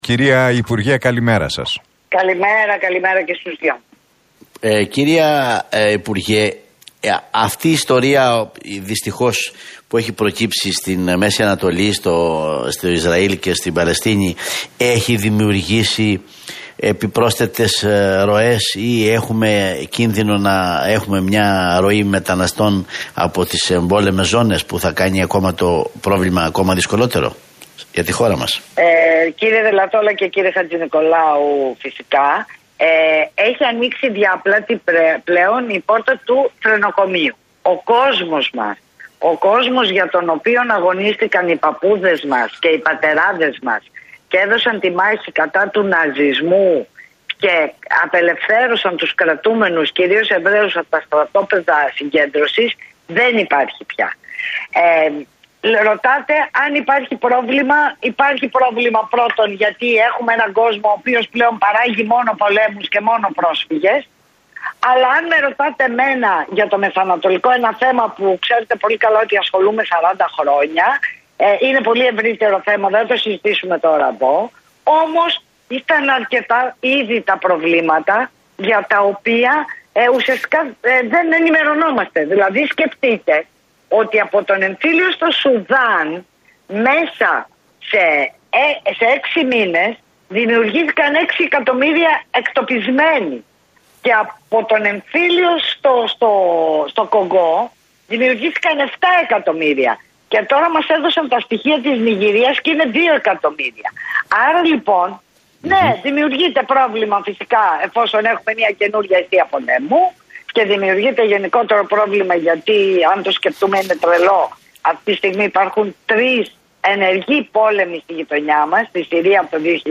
Βούλτεψη στον Realfm 97,8: Έχουμε έναν κόσμο που πλέον παράγει μόνο πολέμους και πρόσφυγες